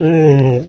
bdog_hurt_0.ogg